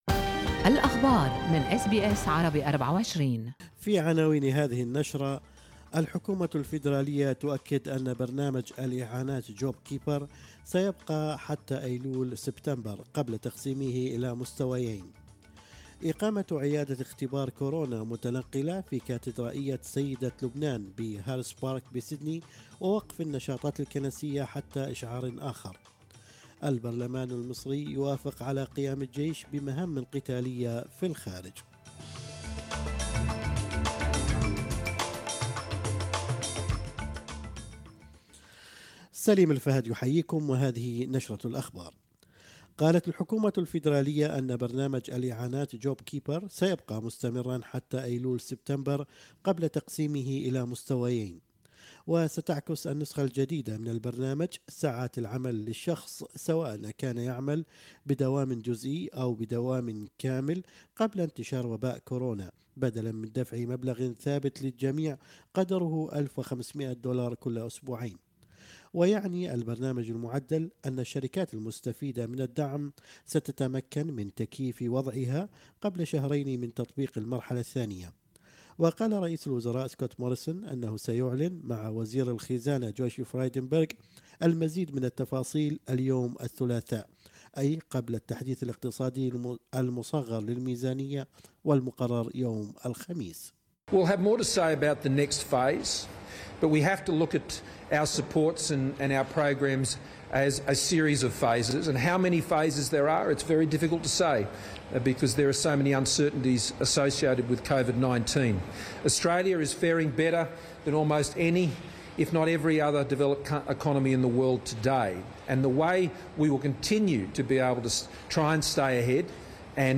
نشرة أخبار الصباح 21/7/2020
news_21_july.mp3